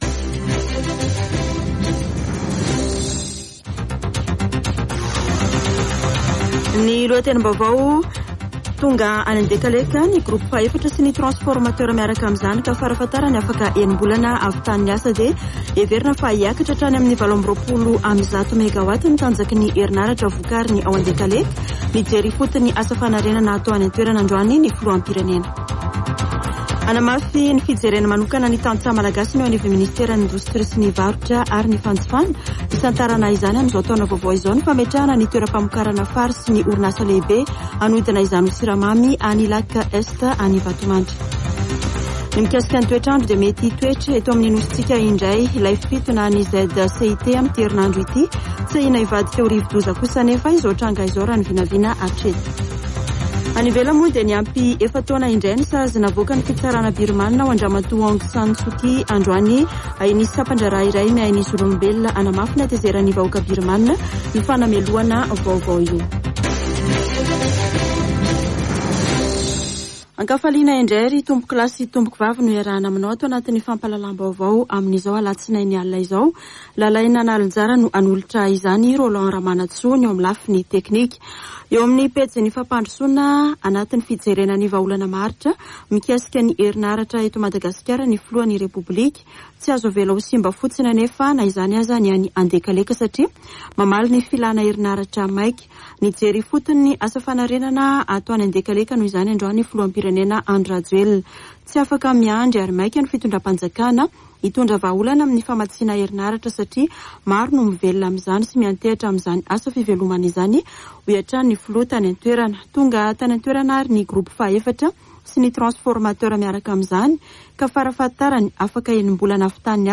[Vaovao hariva] Alatsinainy 10 janoary 2022